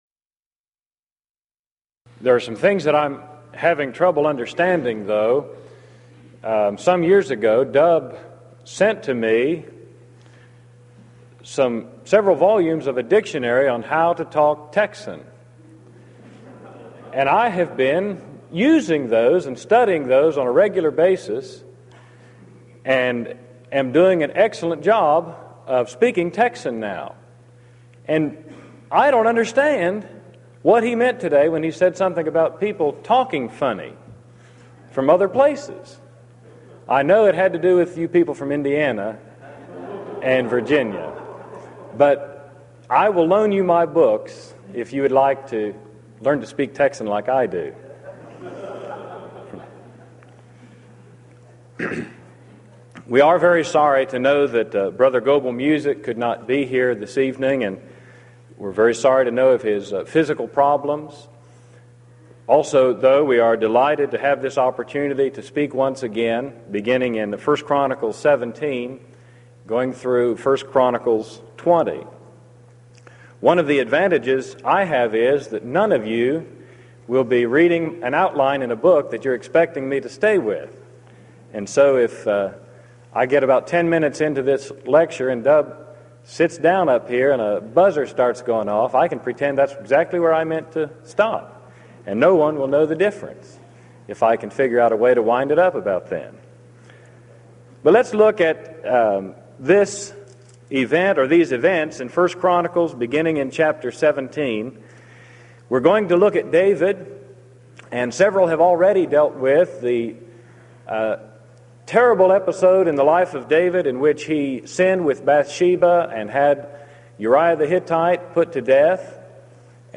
Event: 1993 Denton Lectures